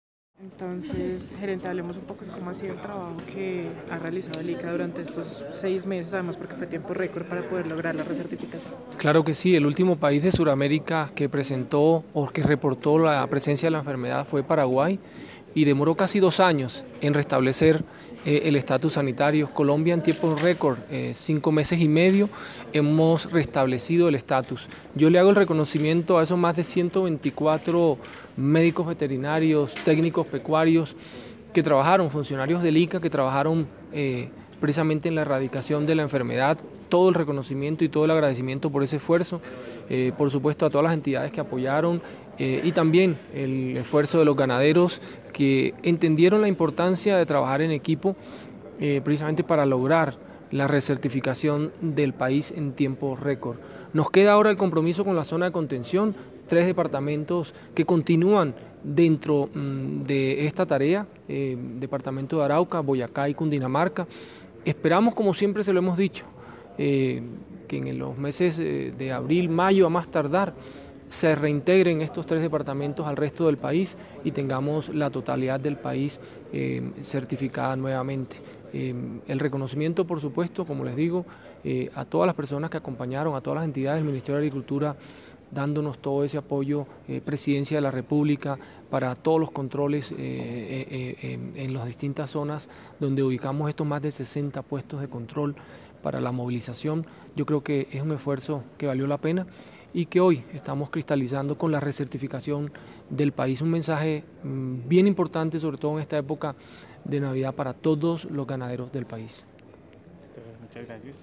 Rueda de prensa en la Presidencia de la República
Declaraciones-Gerente-General-ICA